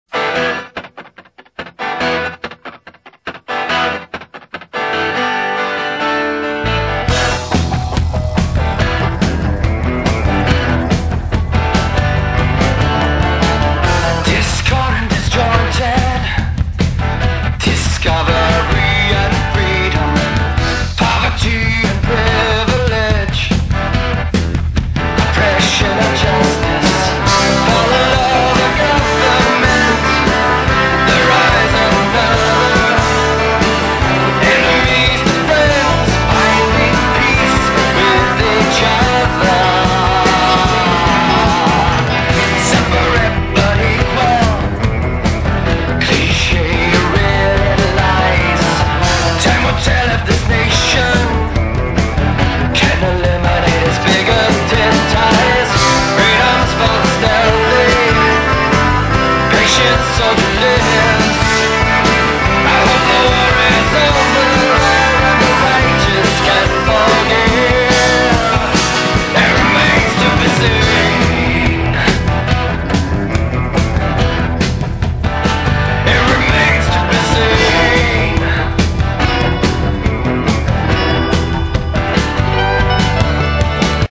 Modern Rock and Pop